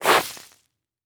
sfx_foot_sand_heavy.wav